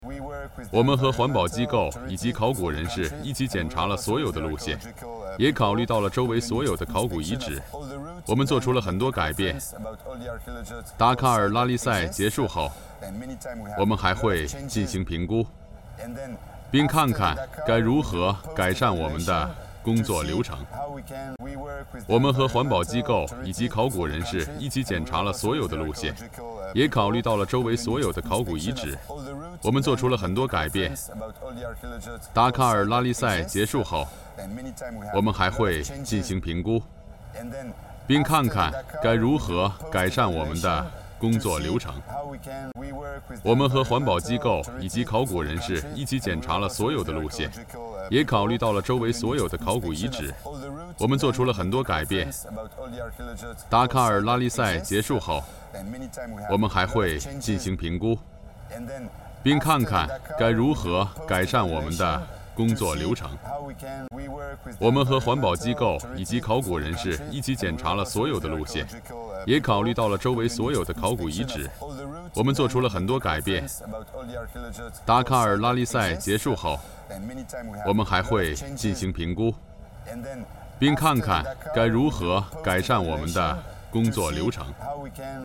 国语中年大气浑厚磁性 、沉稳 、娓娓道来 、男纪录片 、150元/分钟男11 国语 男声 纪录片 斯里兰卡纪录片片段02 大气浑厚磁性|沉稳|娓娓道来